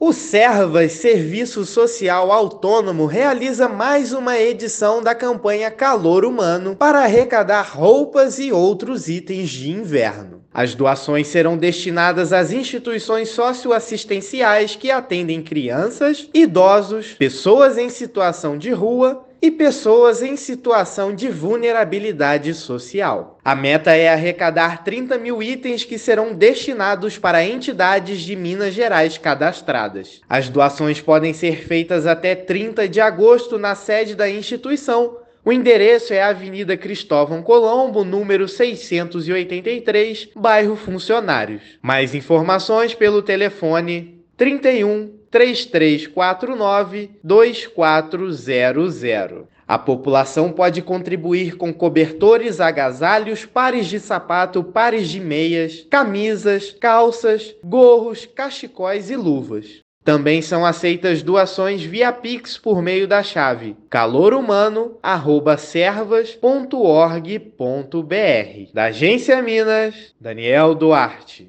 [RÁDIO] Servas faz campanha para arrecadar itens de inverno: saiba como doar
Doações podem ser feitas até 30/8 na sede da instituição ou nos pontos de coleta em Belo Horizonte e Nova Lima. Ouça matéria de rádio: